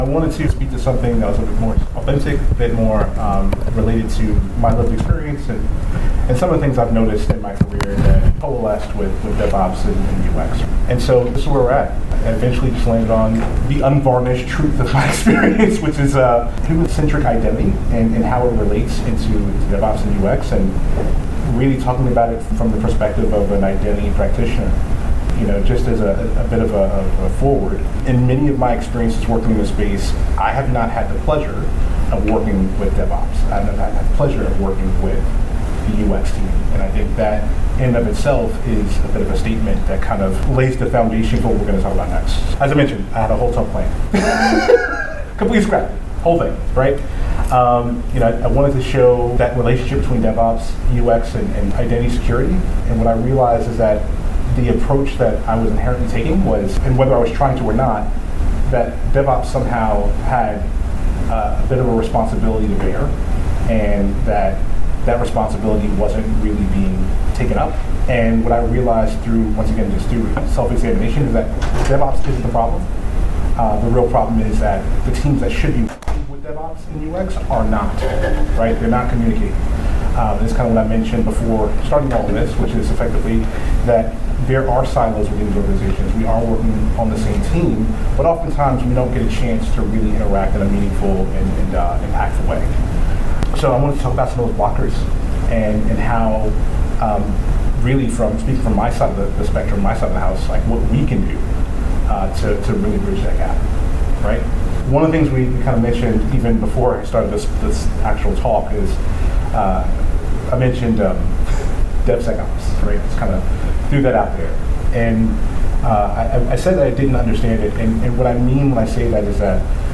The speaker emphasizes the need for collaboration between DevOps, UX, and identity security teams, rather than working in isolated silos.